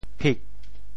噼 部首拼音 部首 口 总笔划 16 部外笔划 13 普通话 pī 潮州发音 潮州 pig4 文 中文解释 噼pī ⒈用刀、斧等工具破开：～竹。